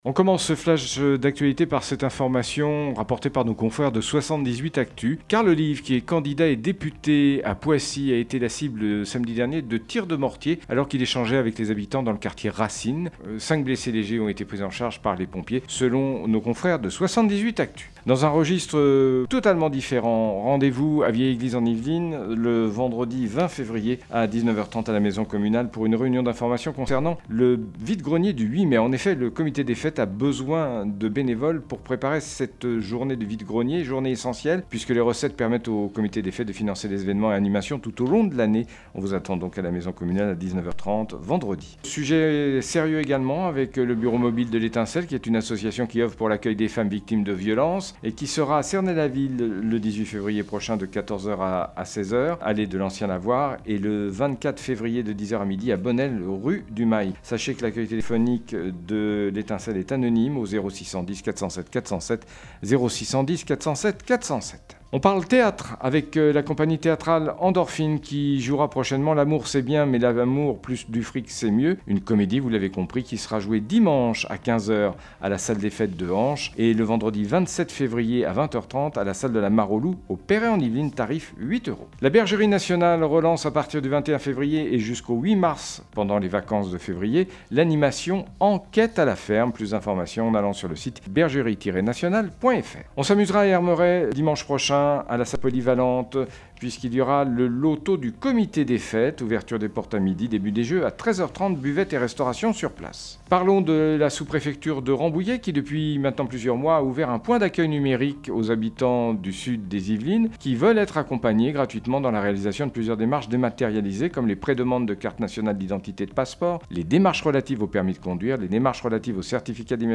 17.02-flash-local-matin.mp3